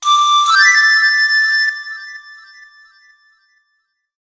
دانلود آهنگ هشدار موبایل 39 از افکت صوتی اشیاء
دانلود صدای هشدار موبایل 39 از ساعد نیوز با لینک مستقیم و کیفیت بالا
جلوه های صوتی